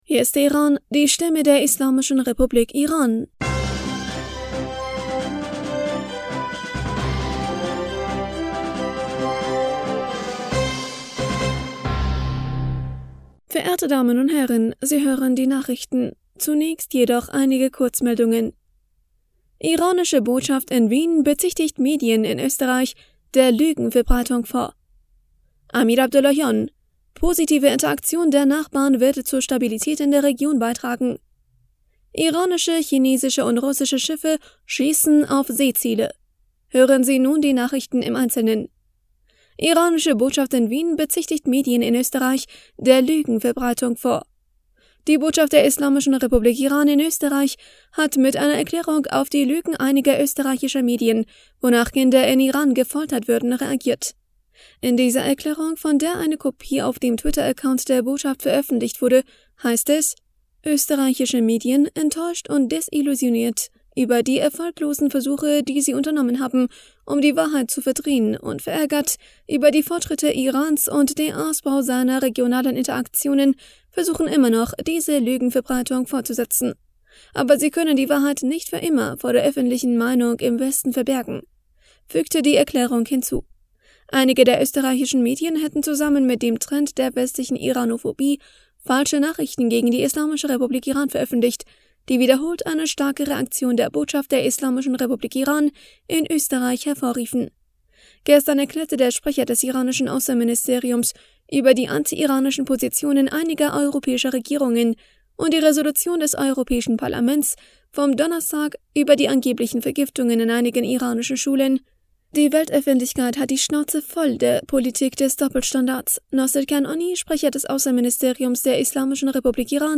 Nachrichten vom 18. März 2023